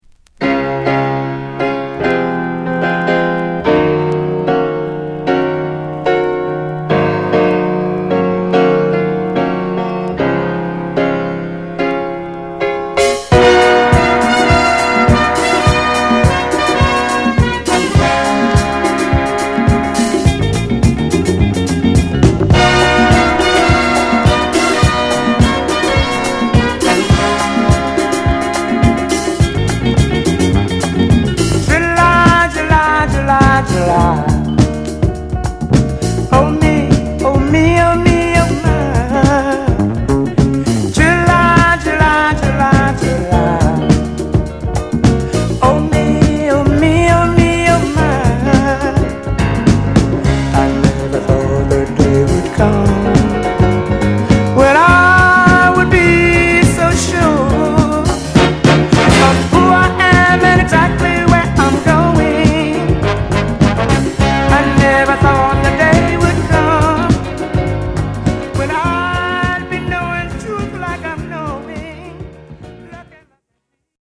軽い雰囲気と随所に入るホーンも印象的なGoodソウル！イントロのピアノフレーズはサンプリングにも！